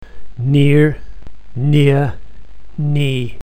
However, a newer, monophthongal pronunciation is becoming common in Britain, and is regular in Australia, New Zealand and South Africa: instead of and you will hear legnthened versions of DRESS and KIT: and .
Rhotic RP, South of England Newer Non-rhotic